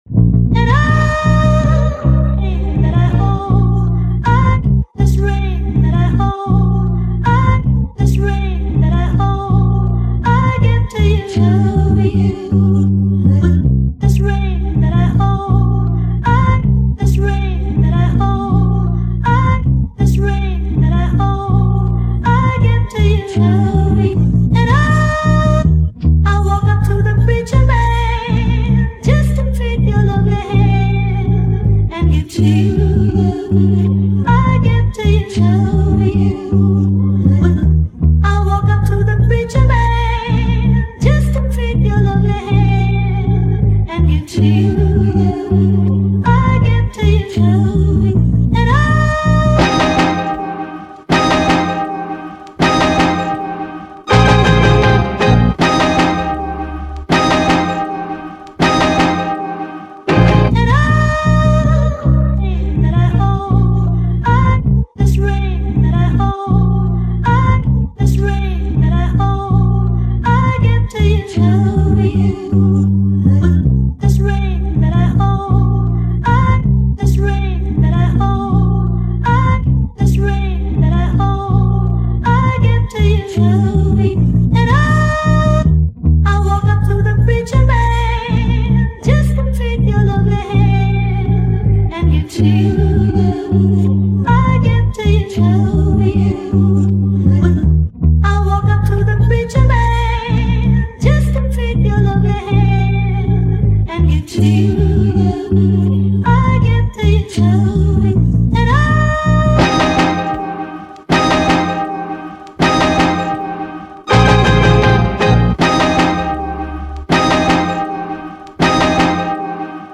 2025 in Hip-Hop Instrumentals